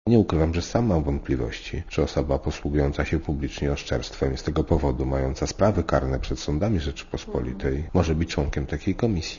Mówi Tomasz Nałęcz